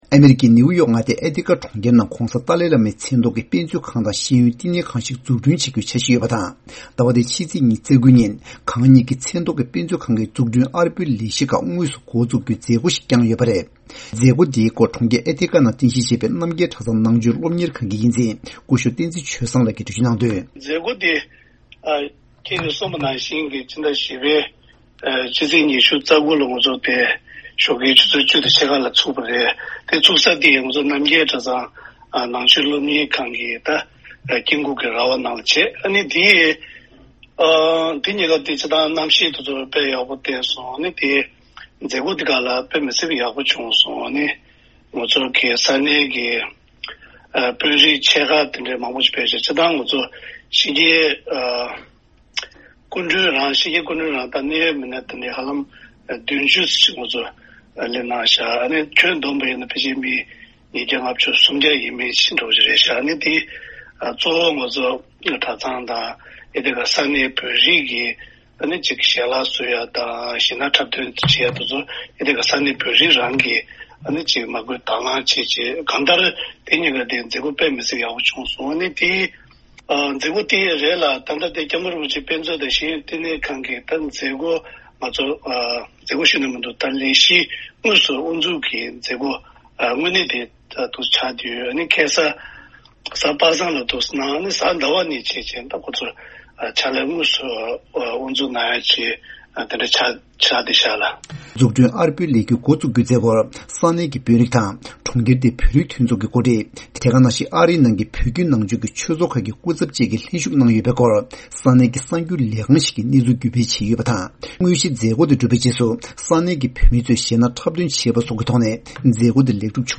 བཅར་འདྲི་ཞུས་ཡོད།